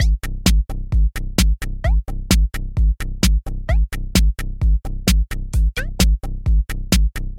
Bass02
描述：BPM130 nonEQ nonReverb nonWidener. Created this sound by using Steinberg HalionSonic2 Voltage.
标签： loop electronic electro synth techno house dance bass
声道立体声